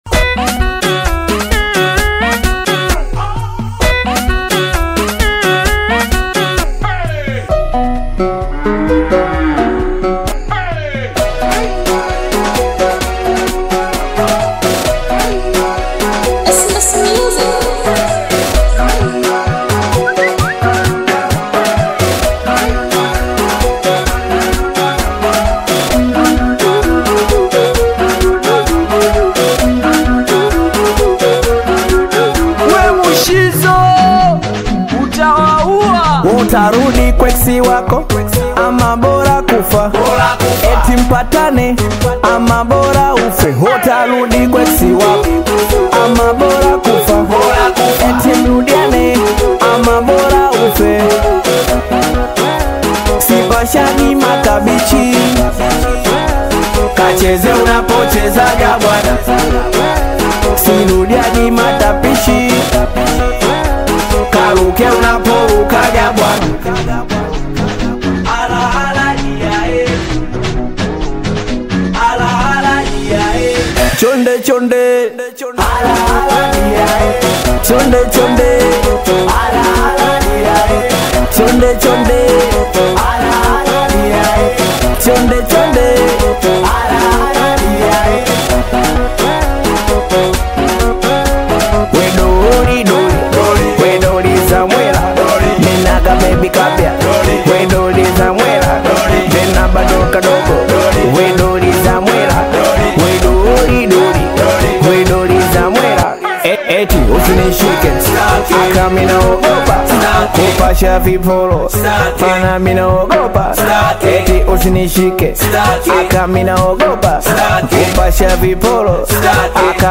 singeli refix